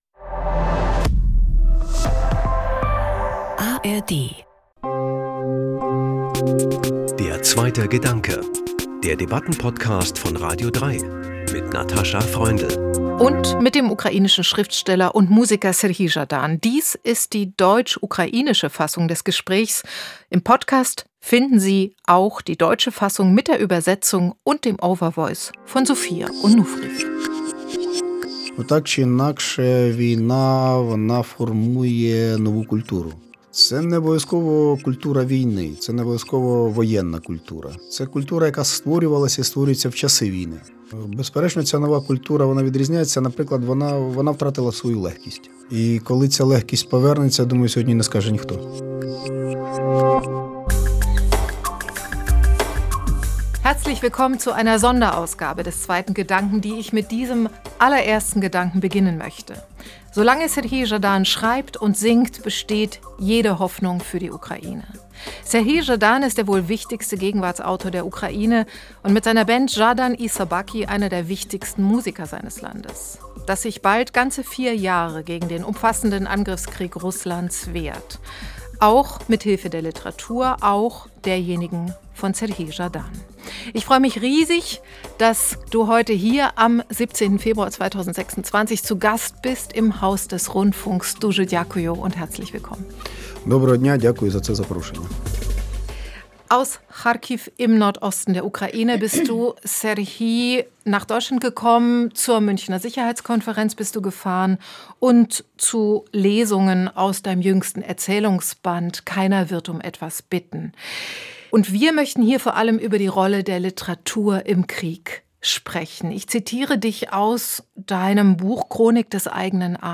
Deutsch-Ukrainisch ohne Overvoice.